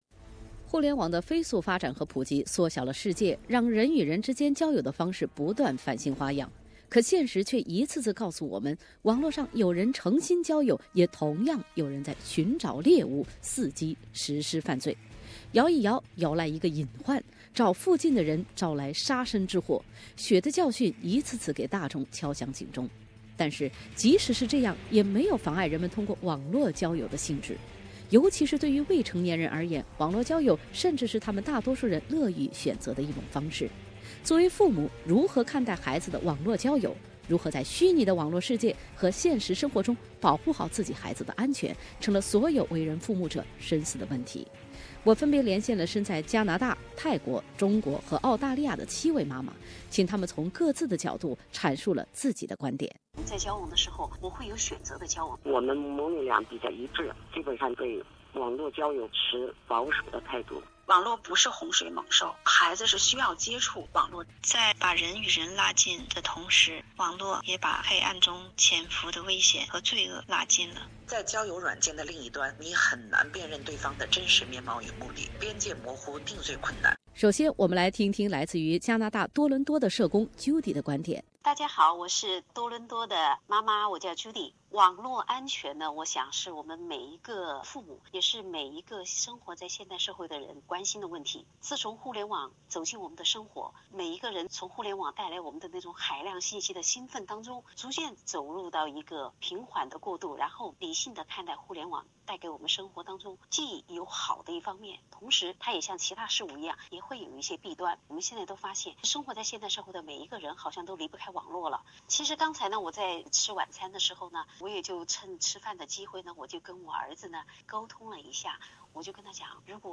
Depth:Canada, Thailand, China, Australia, four countries seven mothers concern about online dating safety FINAL INTERVIEW SEVEN MOTHERS IN FOUR DIFFRENT COUNTRY,TO DISCUSS THE SAFETY OF ONLINE DATING